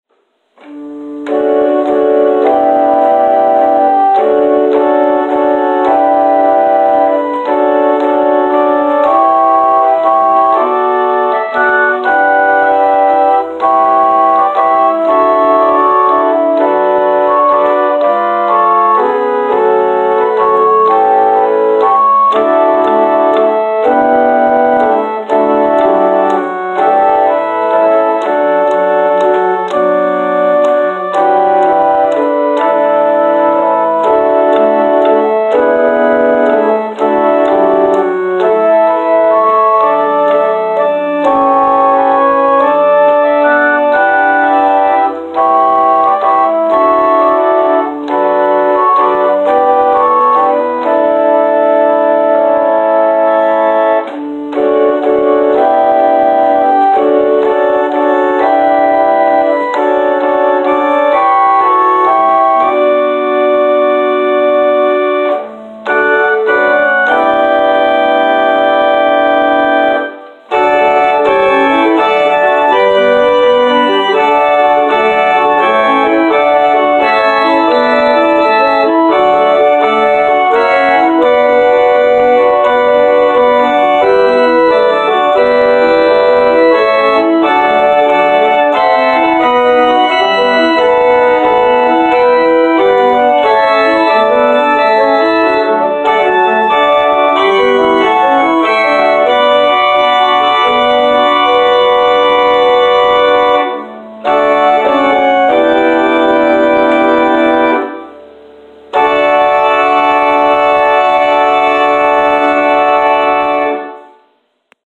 Organ preludes: